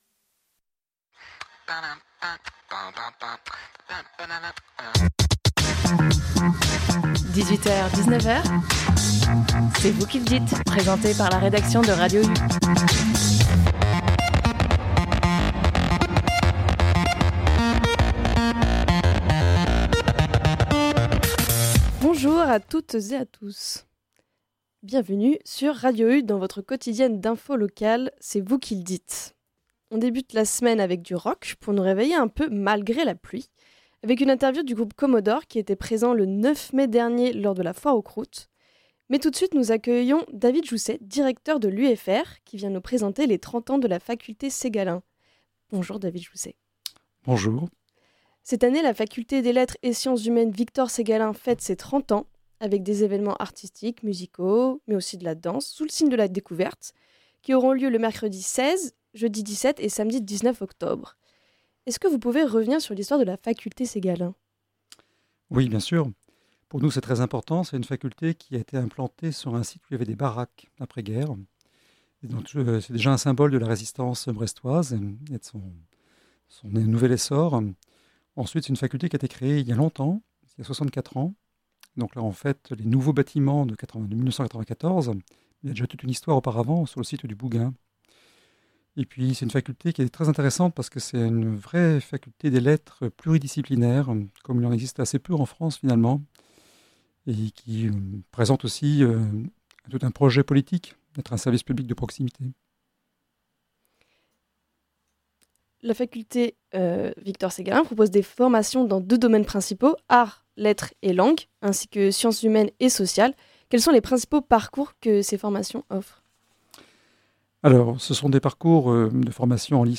En deuxième partie d’émission : une interview du groupe Komodor réalisée lors de la Foire aux croûtes en mai dernier.